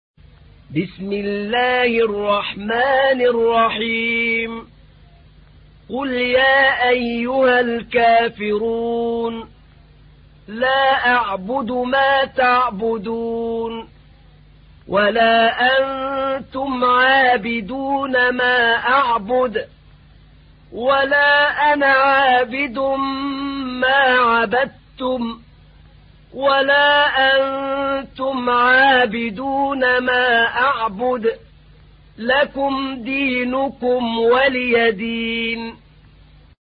تحميل : 109. سورة الكافرون / القارئ أحمد نعينع / القرآن الكريم / موقع يا حسين